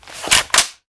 lmg_draw.wav